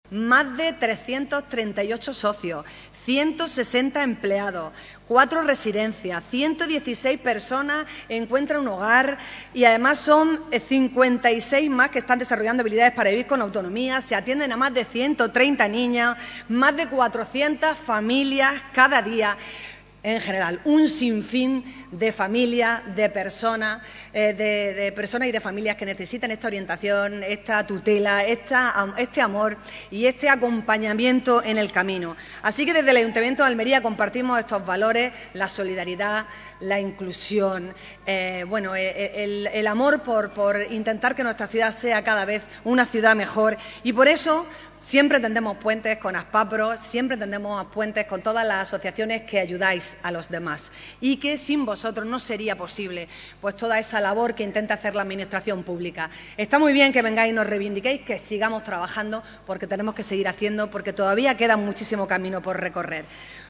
ALCALDESA-GALA-60-ANIVERSARIO-ASPAPROS.mp3